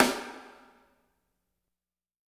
RES SNAREW-R.wav